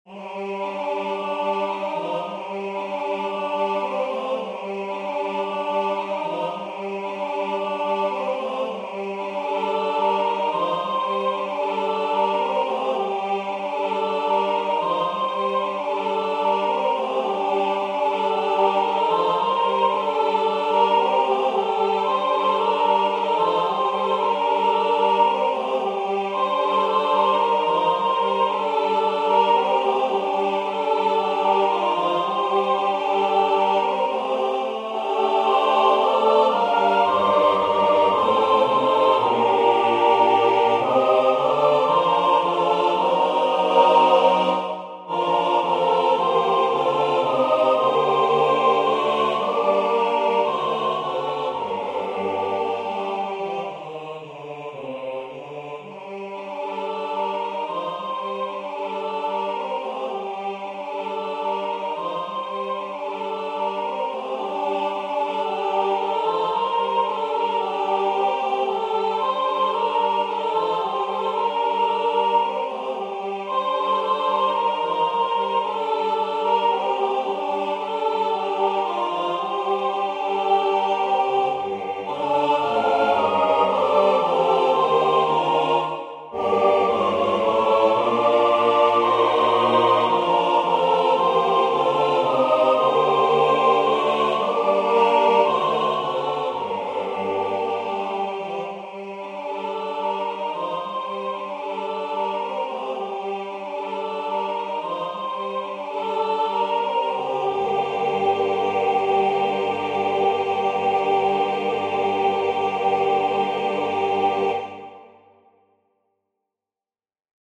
Version Voix Synth